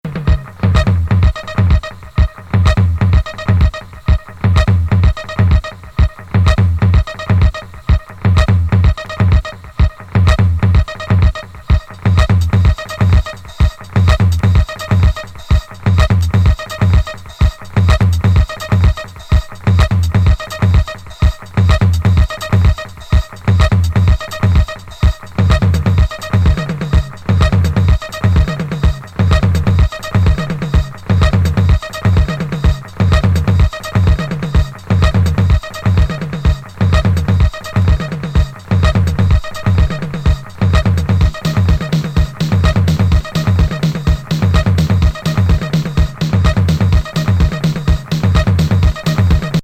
ドラム・トラックのみで構成されたA面は圧巻。